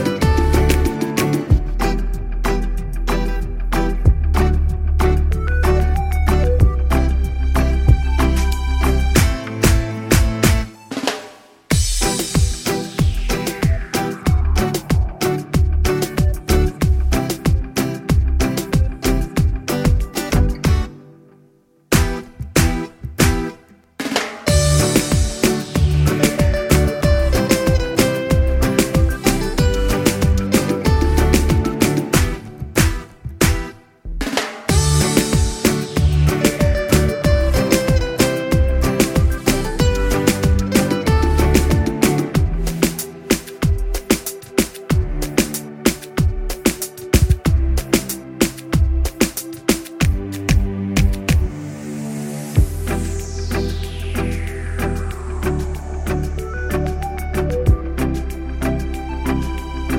Remix with Backing Vocals Pop (2010s) 3:08 Buy £1.50